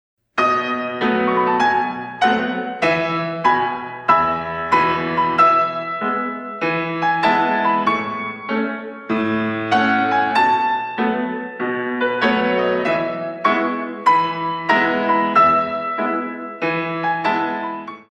32 Counts